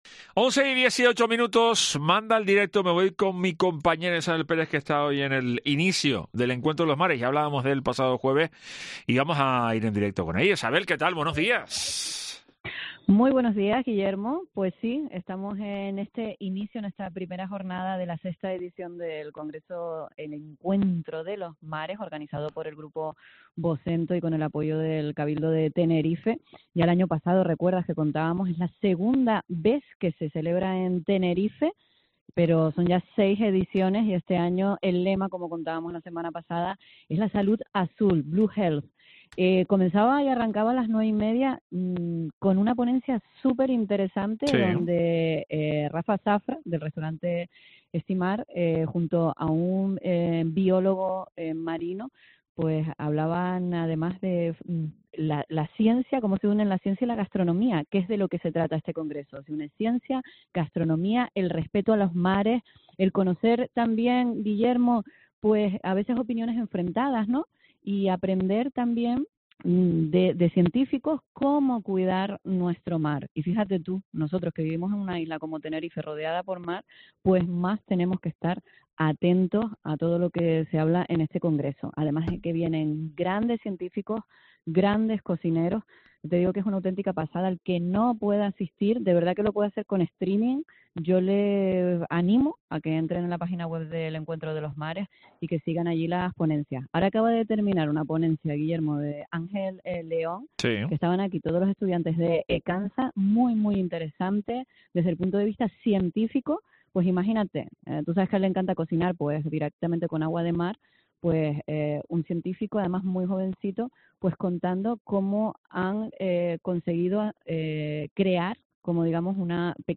Entrevista a Lope Afonso, vicepresidente del Cabildo, por el Encuentro de los Mares